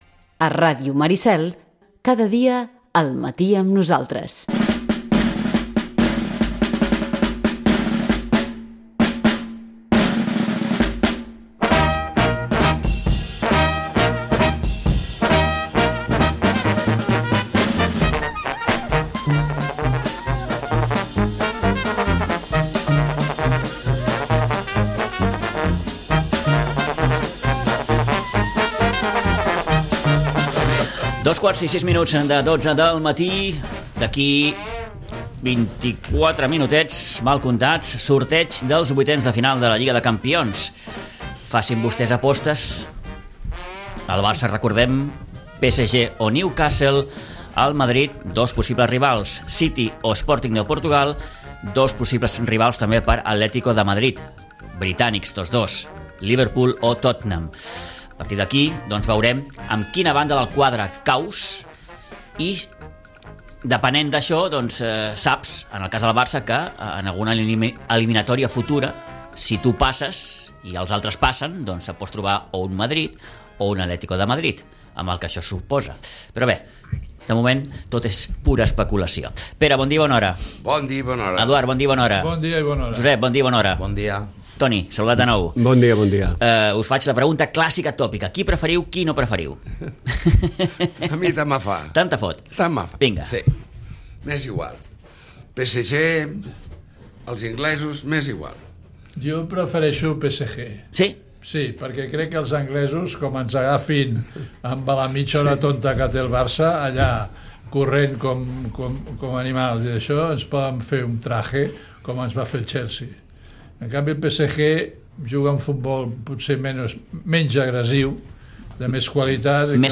La tertúlia esportiva
El Barça coneixerà avui el seu rival per als vuitens de final de la Champions i la lesió de Frenkie De Jong. Repassem l'actualitat futbolera amb els nostres tertulians habituals de cada divendres.